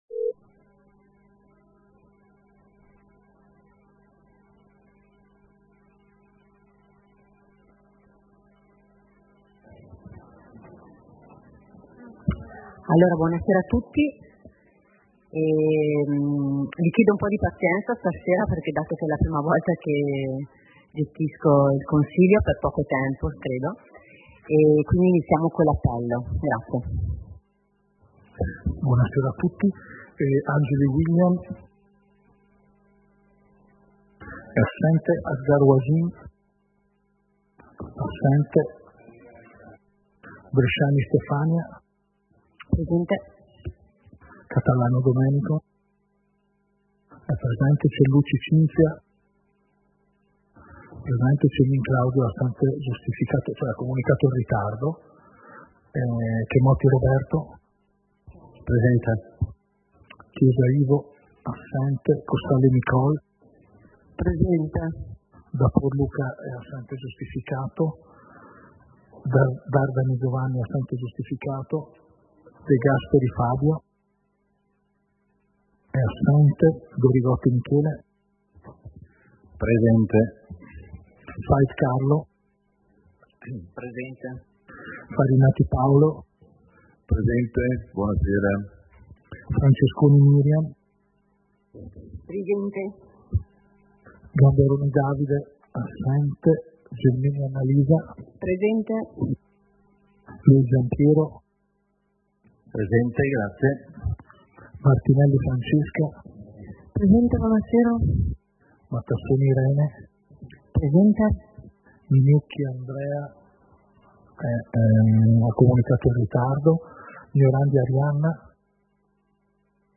Seduta del consiglio comunale - 26.11.2025